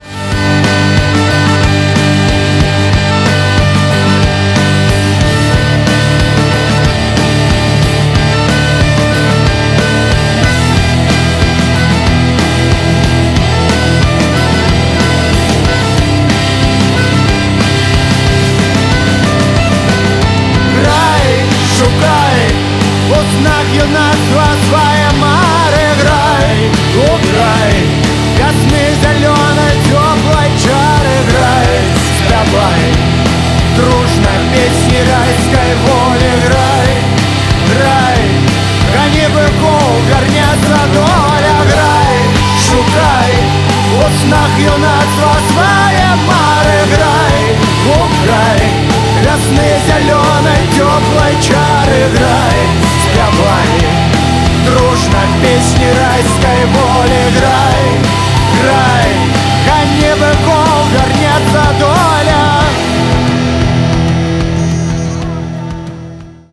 • Качество: 256, Stereo
Rock